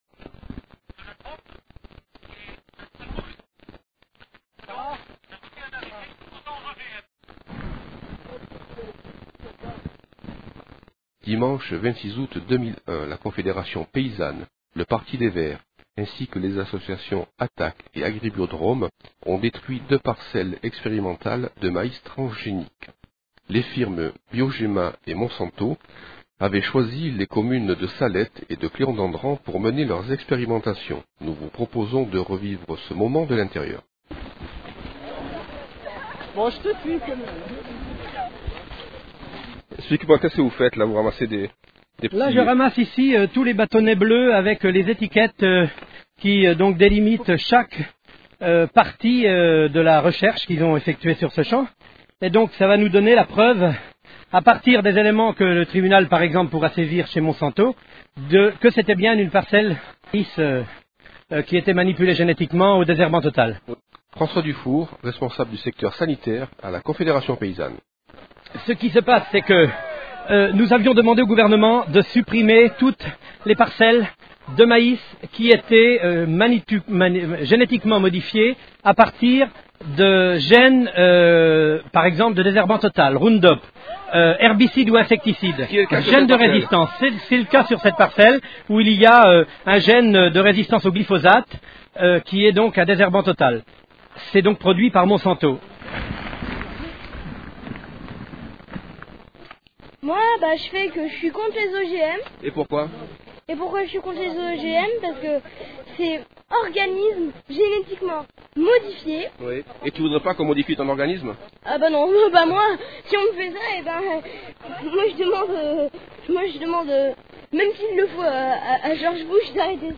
Les Interviews de Radio-Méga
le 26 Août 2001 à Salette